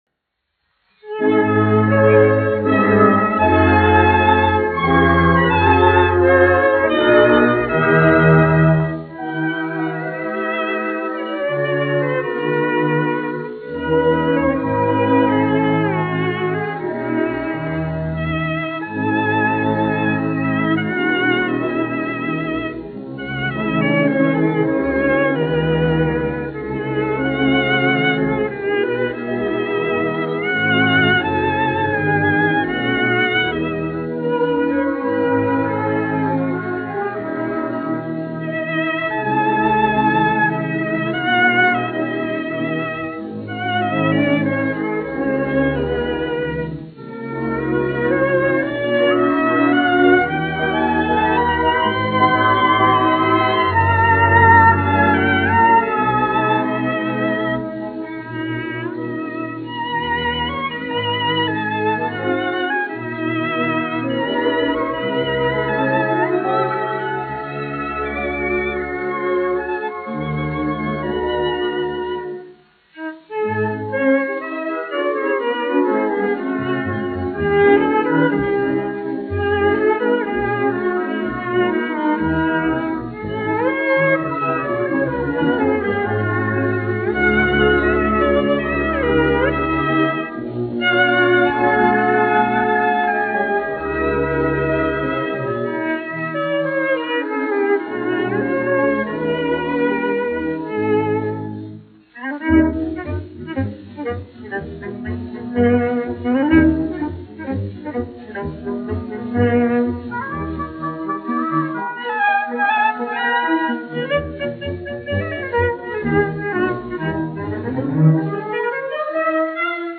1 skpl. : analogs, 78 apgr/min, mono ; 25 cm
Operas--Fragmenti
Orķestra mūzika
Latvijas vēsturiskie šellaka skaņuplašu ieraksti (Kolekcija)